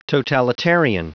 Prononciation du mot totalitarian en anglais (fichier audio)
Prononciation du mot : totalitarian